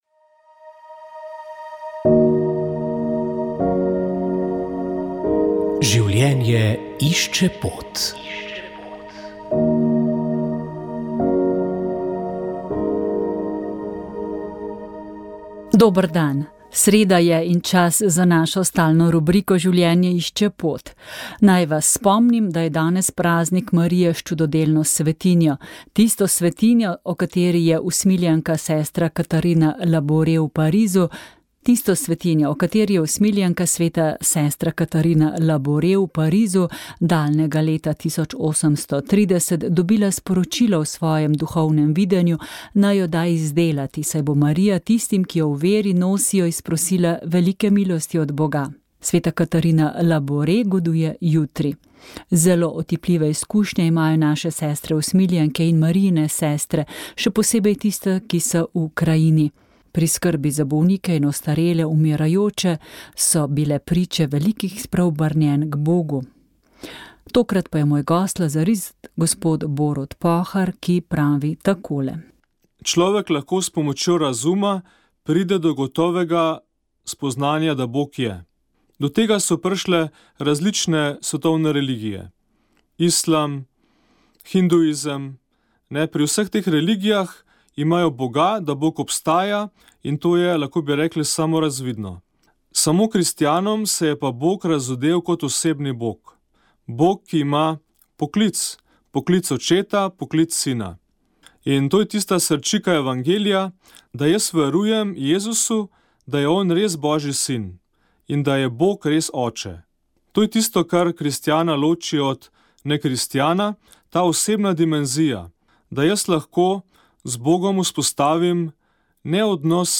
Molile so redovnice - Hčere Marije Pomočnice.